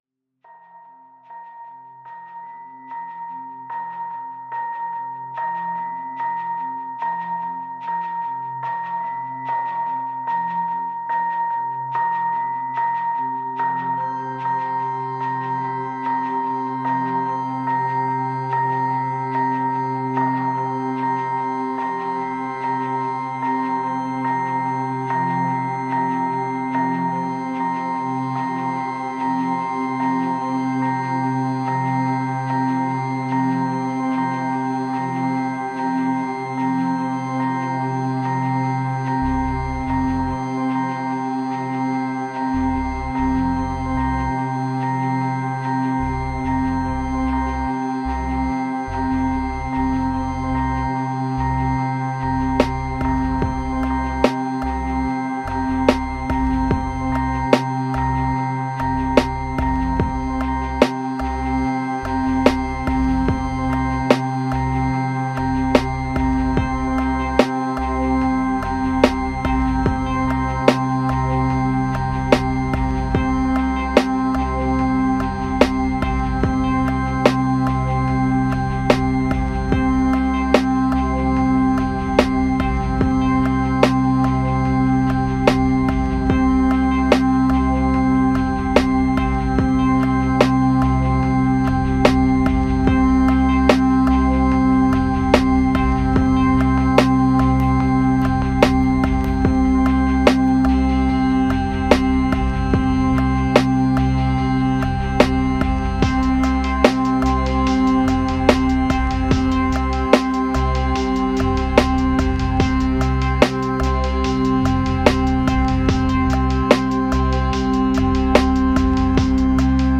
Meditation Moods Epic Hypnotic Relief Peace Uplifting